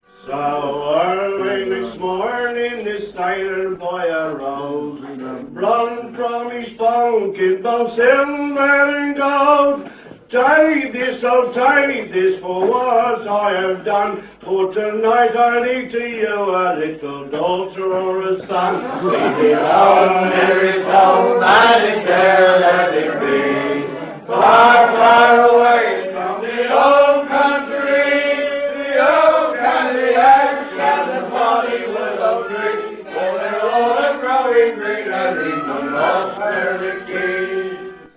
Songs from Country Pubs - Various
These are live pub recordings made on a domestic tape recorder so inevitably there is some background noise and the singers are not always accorded the best of order.